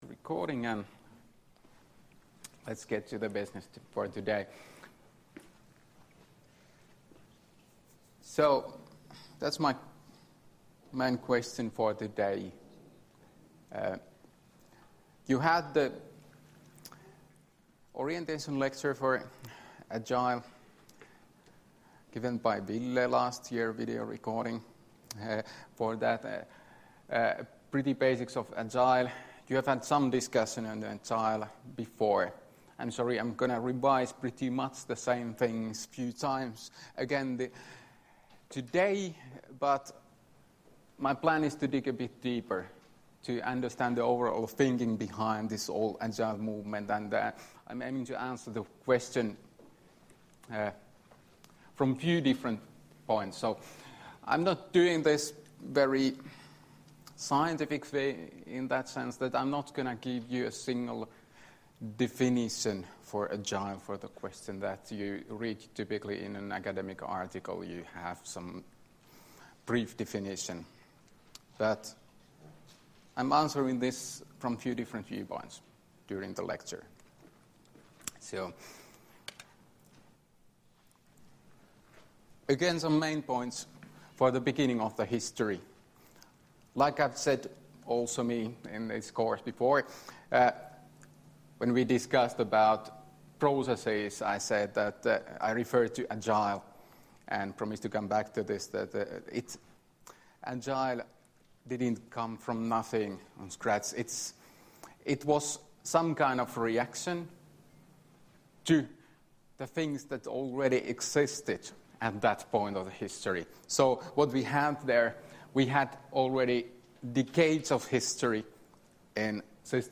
Luento 30.1.2018 42f3074c7d274b6d83c83d093a6a40b6